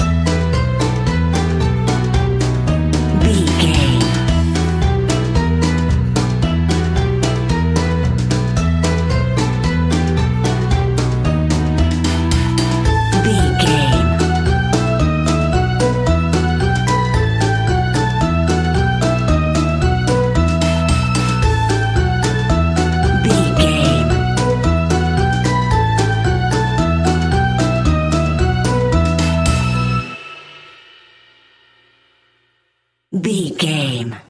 Aeolian/Minor
childrens music
instrumentals
fun
childlike
cute
happy
kids piano